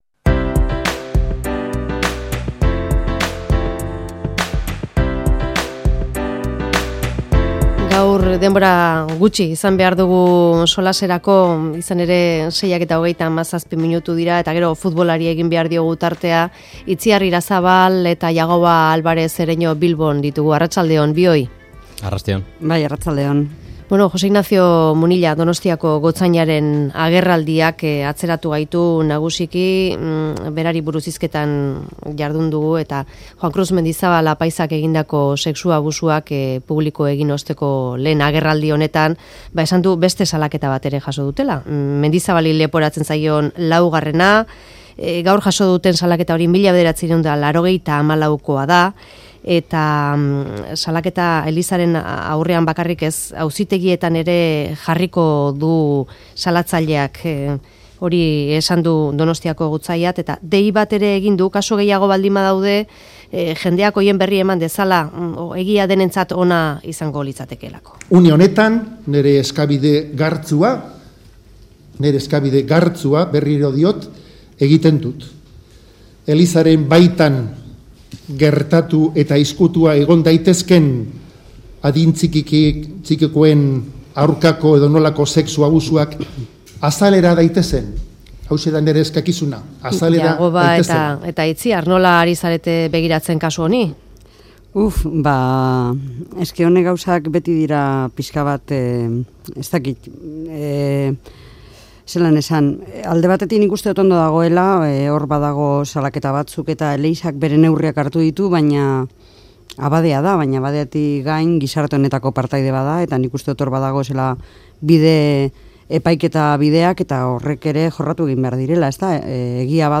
Mezularia|Solasaldia